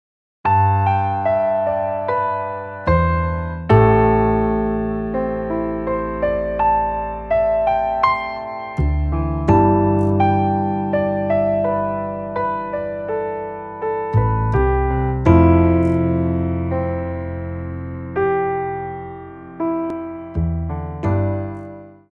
Percussion and Piano Music